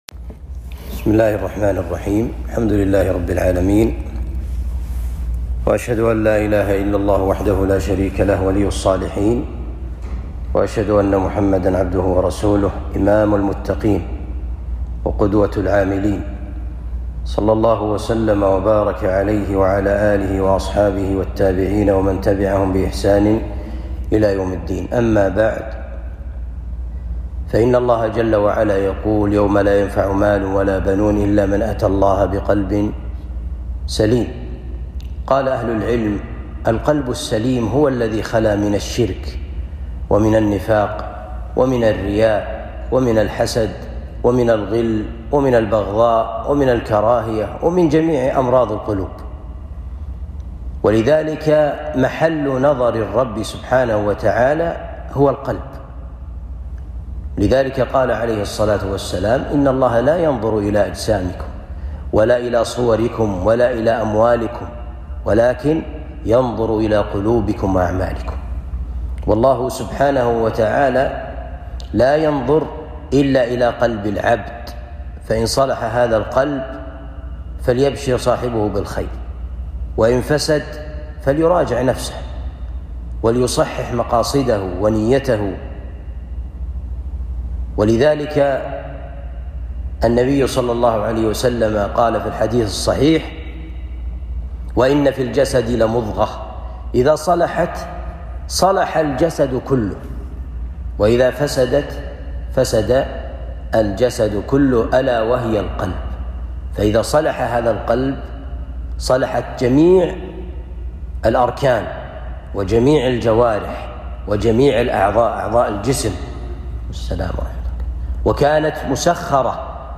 أسباب قسوة القلوب كلمة صوتية